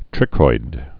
(trĭkoid, trīkoid)